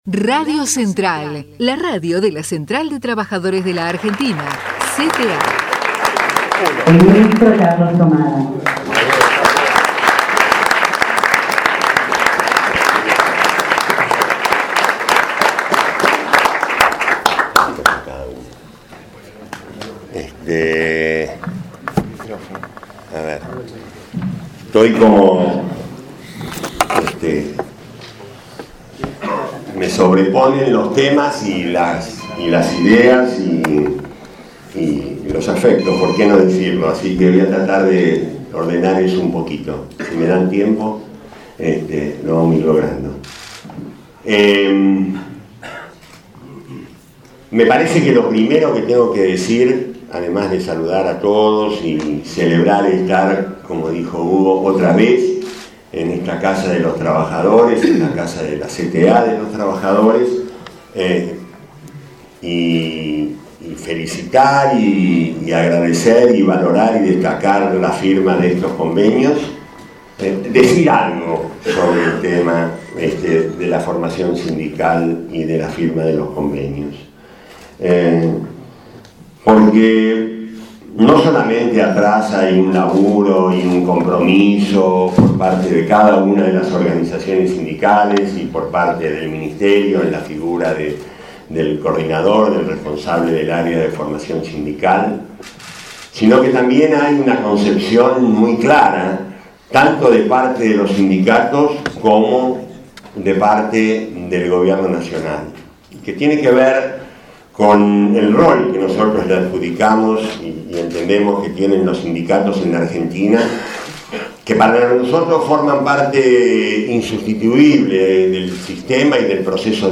tomada_en_la_cta.mp3